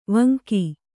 ♪ vanki